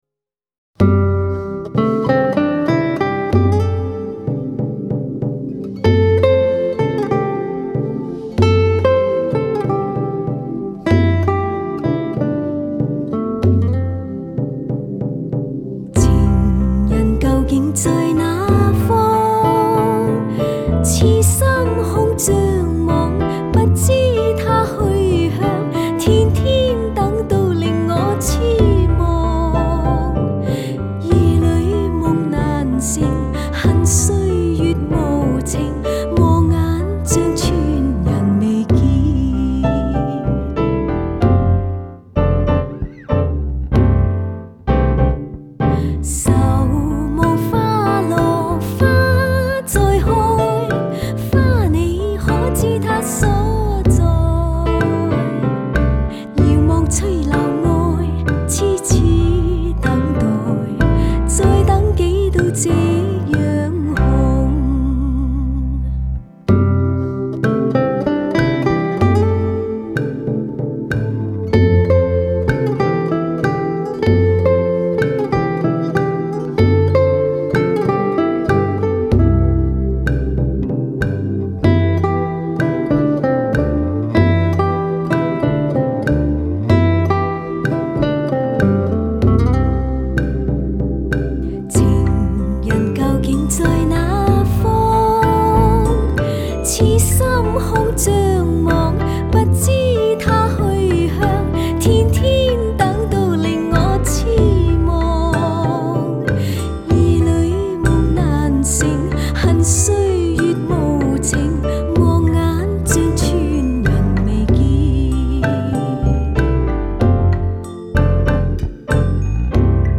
她的歌声温柔甜美，婉转悦耳，清新雅致，犹如一阵阵春风吹入人们的心田。